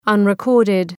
Leave a reply unrecorded Dëgjoni shqiptimin https
{,ʌnrı’kɔ:rdıd}